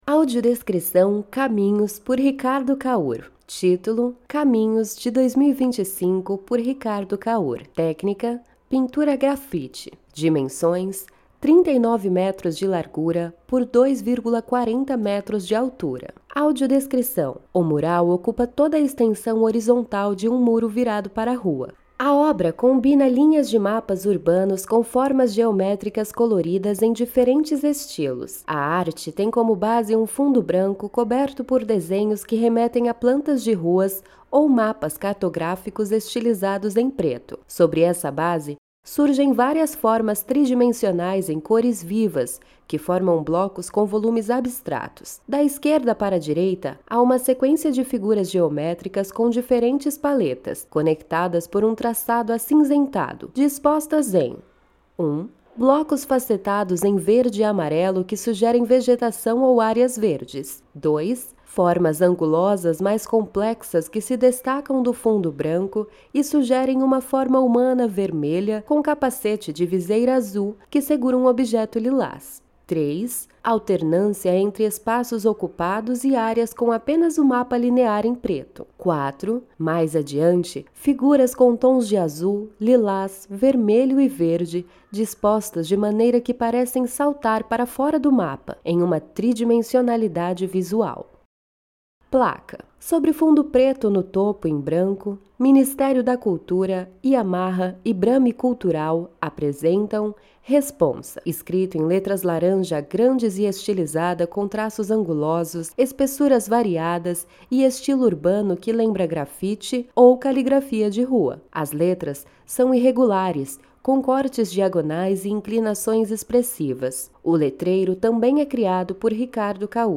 Audiodescrição da Obra